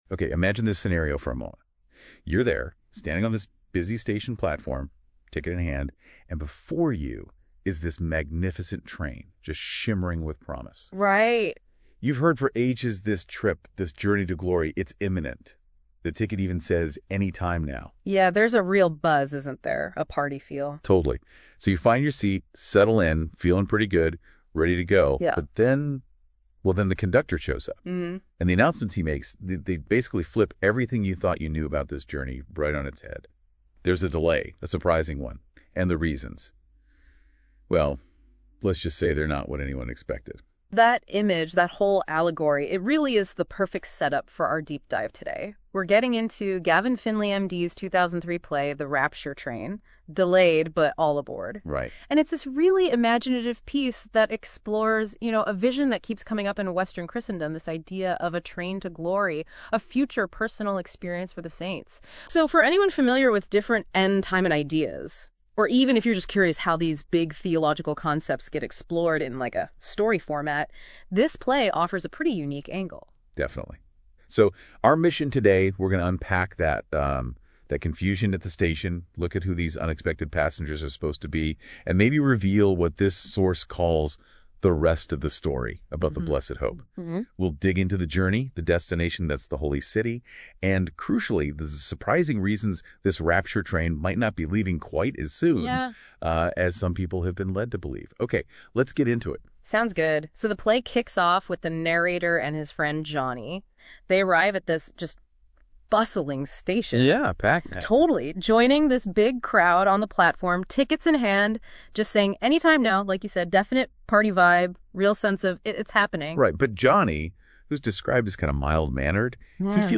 Here is A LINK to the .mp3 AUDIO of an AI two-person commentary on this play by LM Notebook